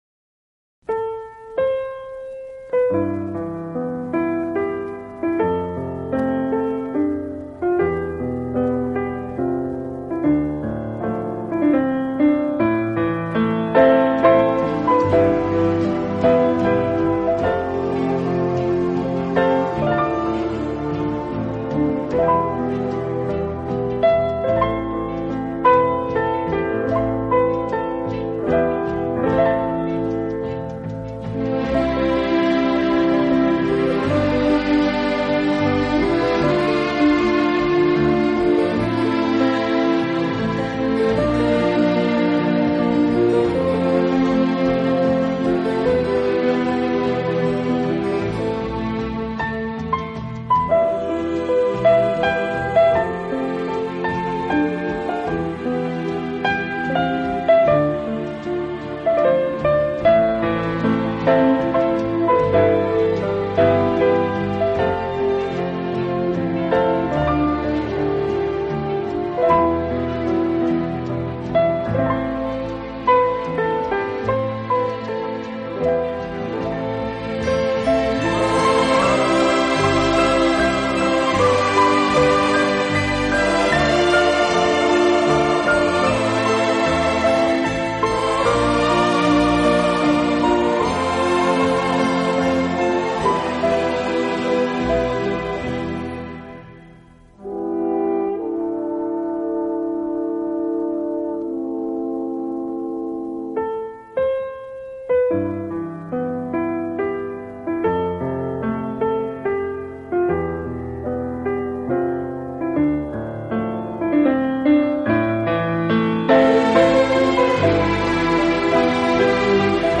1976 | Instrumental |